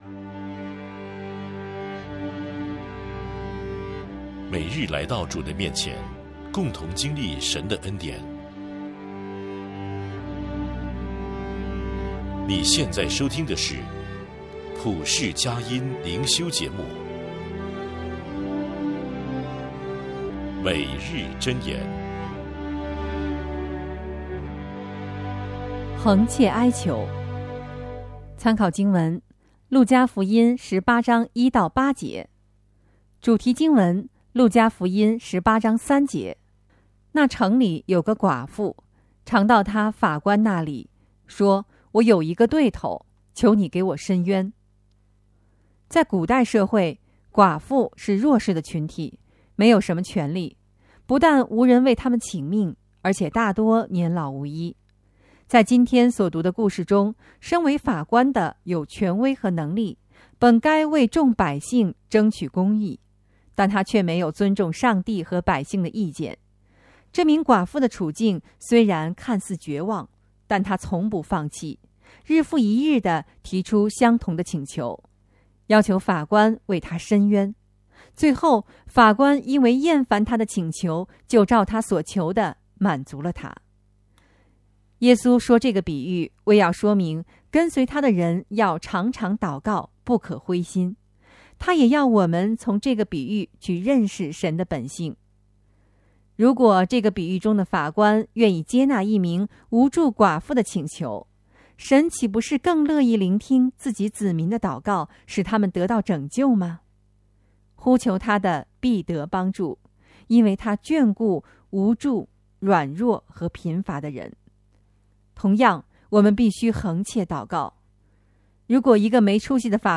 诵读